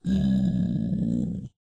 mob / zombiepig / zpig2.ogg